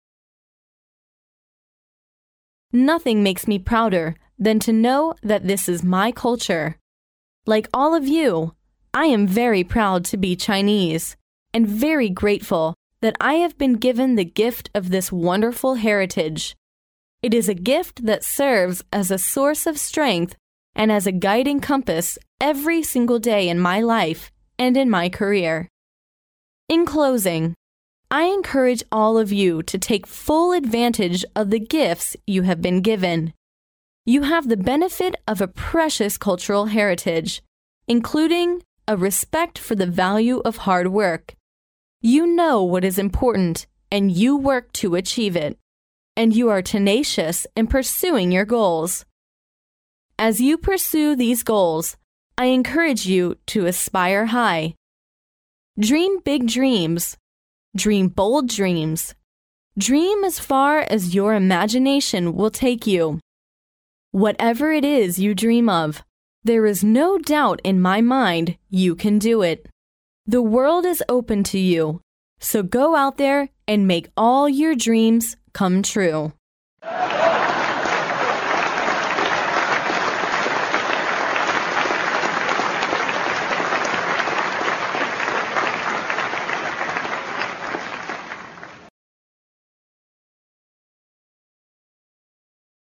名校励志英语演讲 第193期:一切皆有可能(15) 听力文件下载—在线英语听力室
借音频听演讲，感受现场的气氛，聆听名人之声，感悟世界级人物送给大学毕业生的成功忠告。